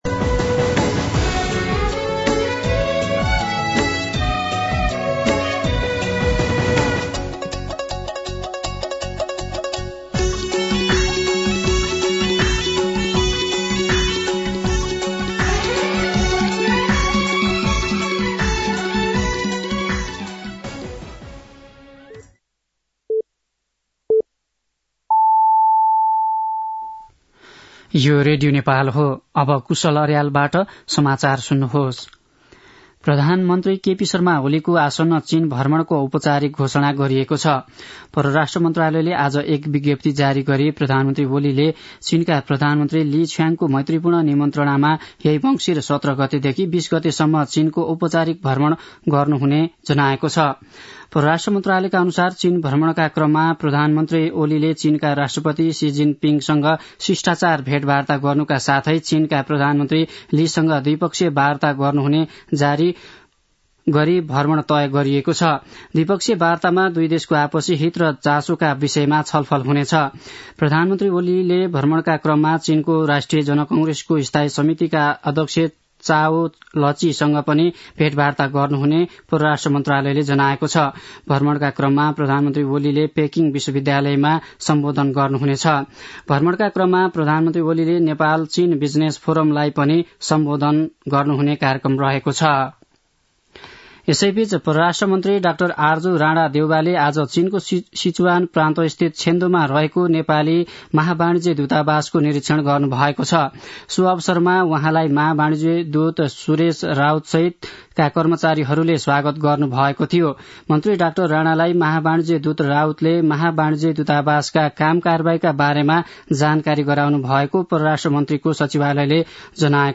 दिउँसो ४ बजेको नेपाली समाचार : १५ मंसिर , २०८१
4-pm-Nepali-News-1-1.mp3